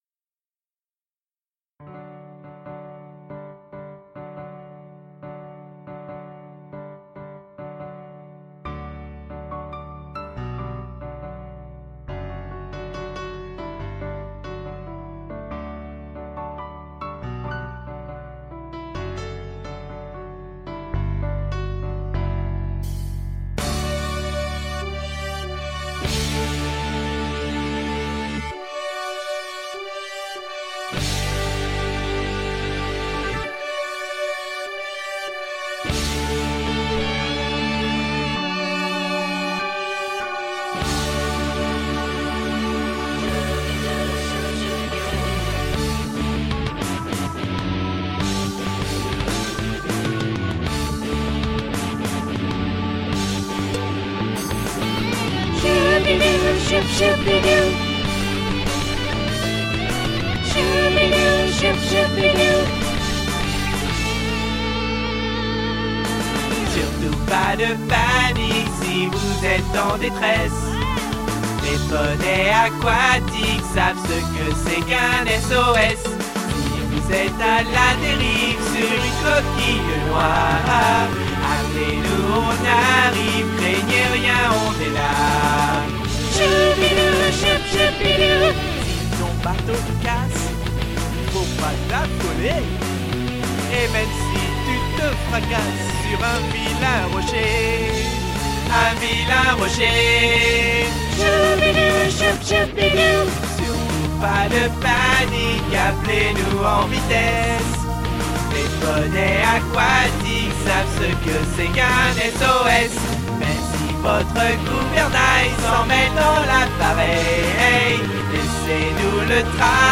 Sur fond de rock mega-cool